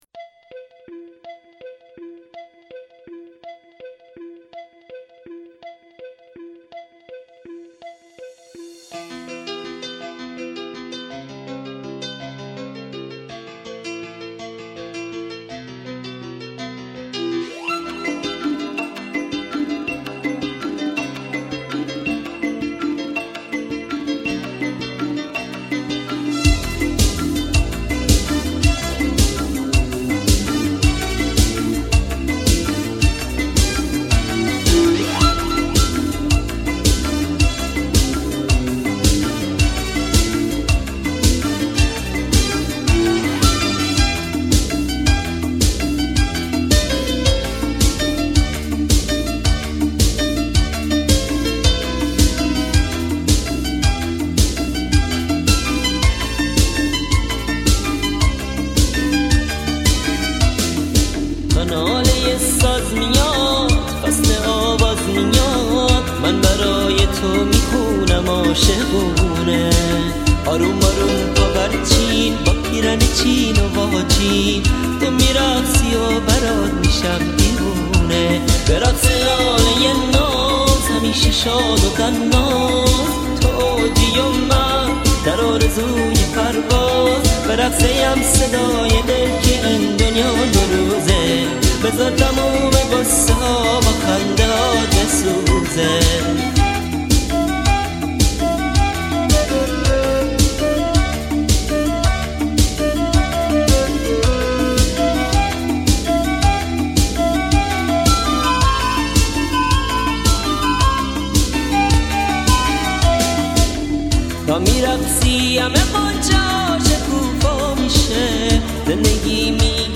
آهنگ ایرانی رقص عروس داماد